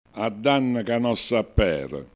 Con tono fermo si indica che si sta per compiere un?azione decisa, quali che siano le conseguenze.